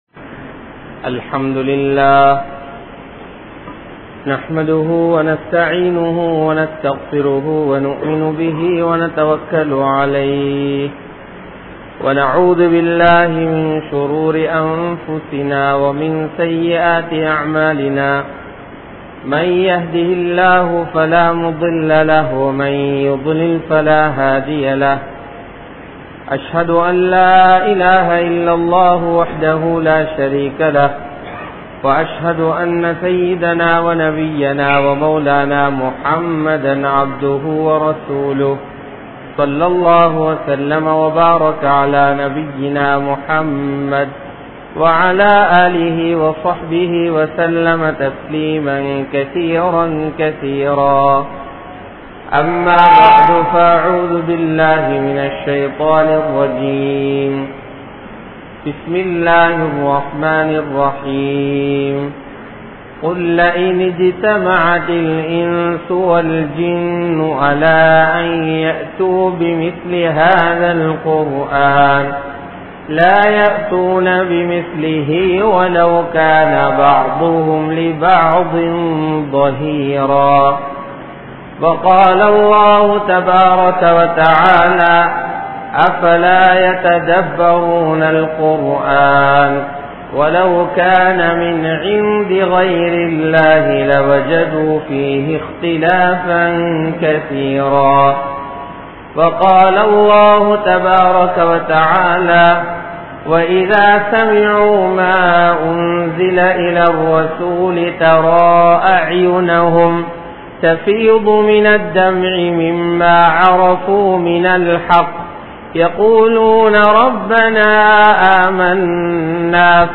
Al-Quranuku Naam Seium Aniyaayam | Audio Bayans | All Ceylon Muslim Youth Community | Addalaichenai